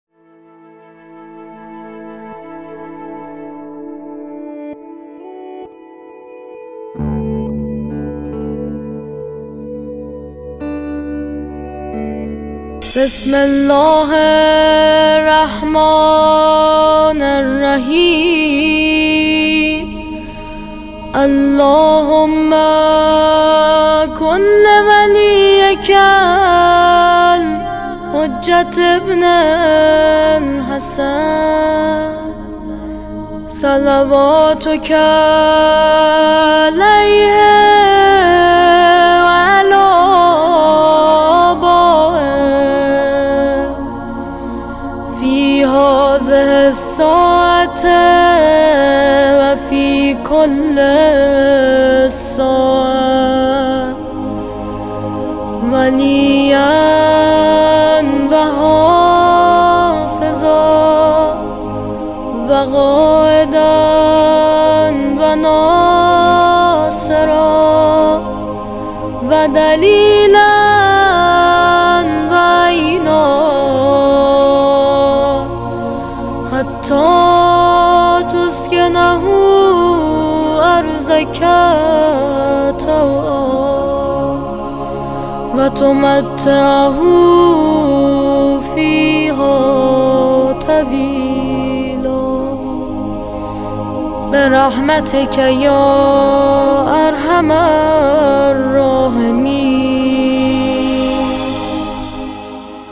دعای سلامتی امام زمان کودکانه